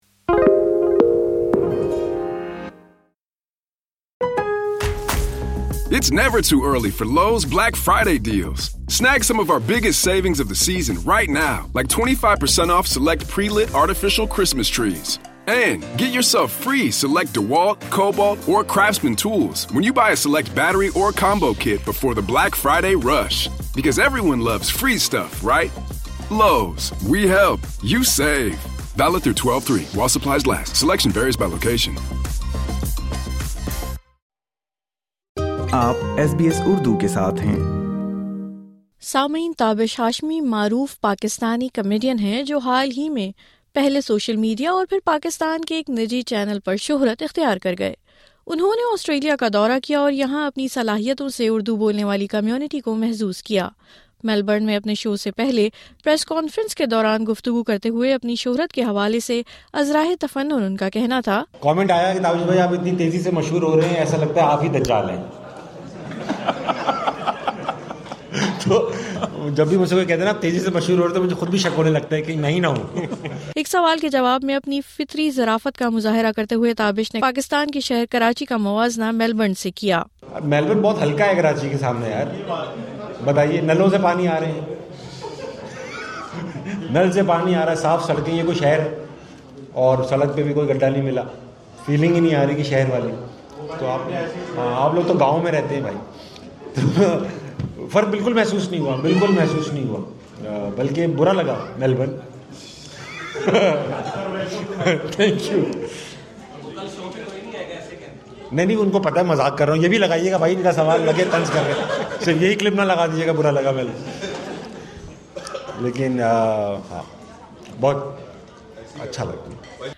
خصوصی گفتگو